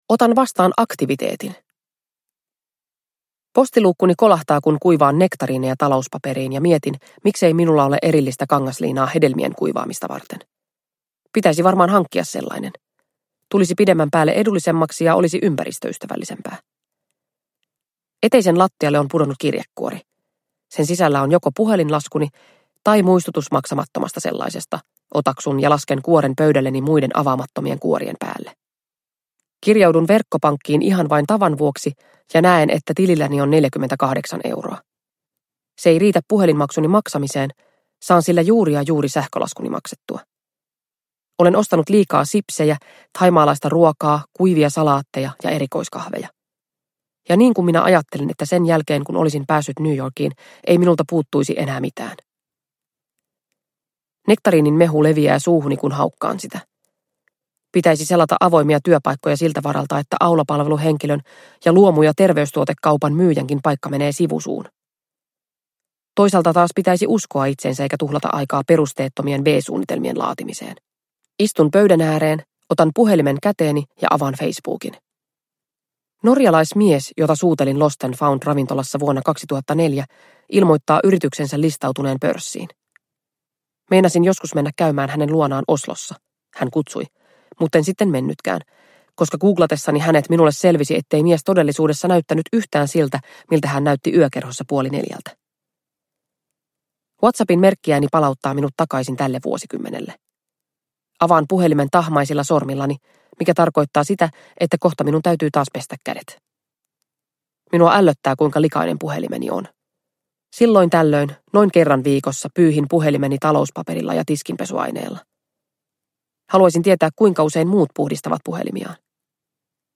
Ehkä tänä kesänä kaikki muuttuu – Ljudbok – Laddas ner